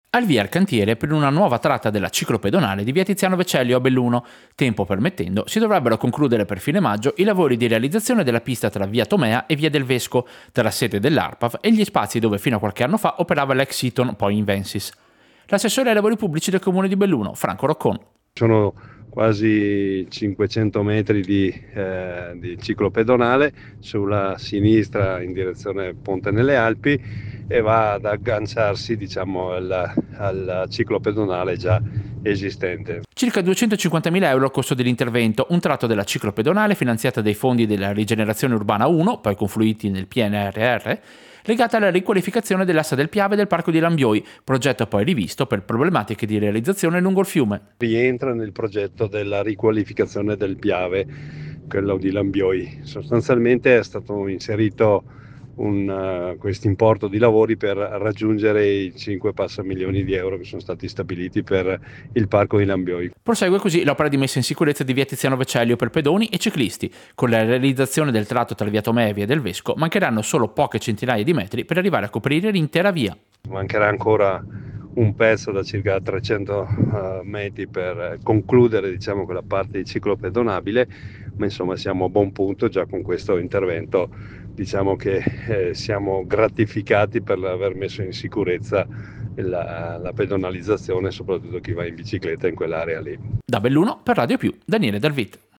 Servizio-Lavori-ciclabile-Belluno-via-Tomea.mp3